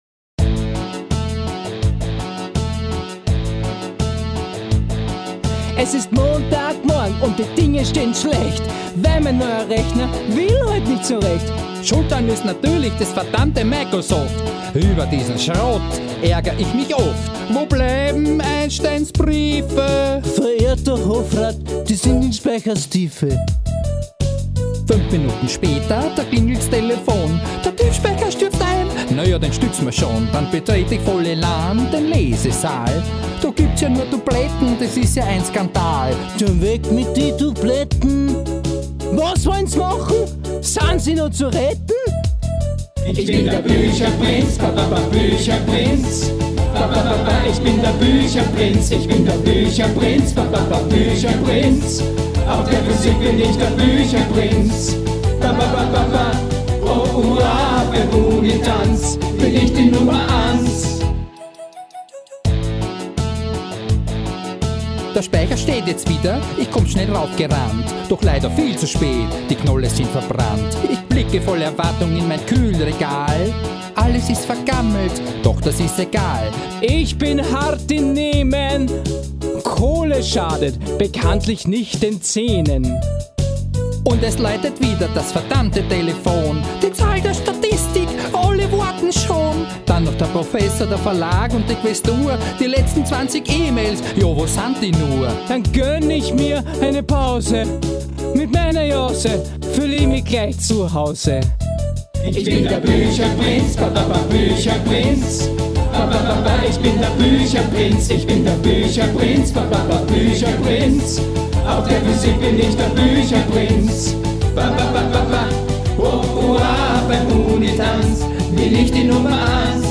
Abschiedsfest
Karaoke-CD "Best of Austropop Vol. 1"
Chor der Österreichischen Zentralbibliothek für Physik Grafik und Videoregie